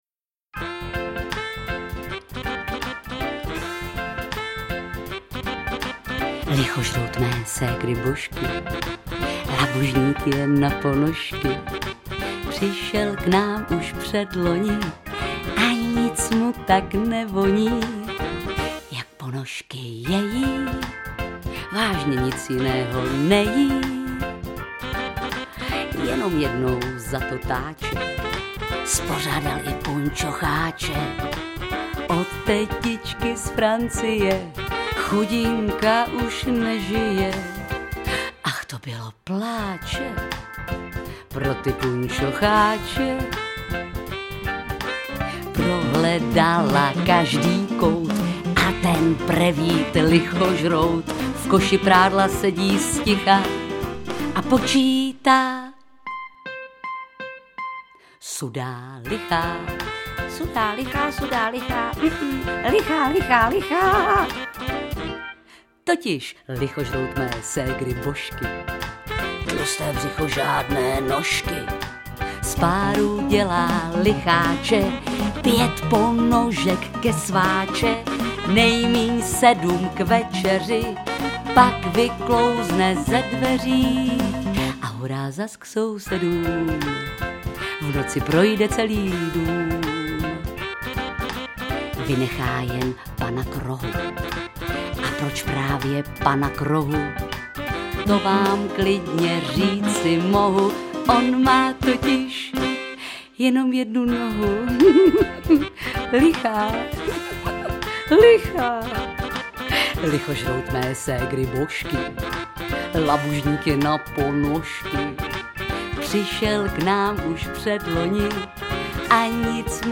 Poslechová